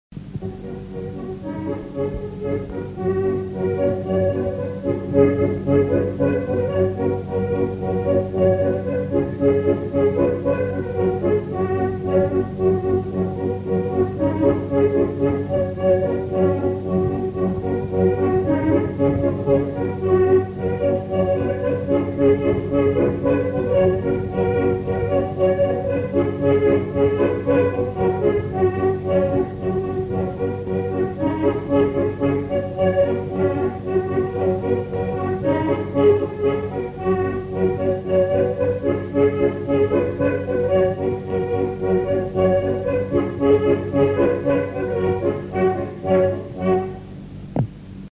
Aire culturelle : Haut-Agenais
Lieu : Monclar d'Agenais
Genre : morceau instrumental
Instrument de musique : accordéon diatonique
Danse : polka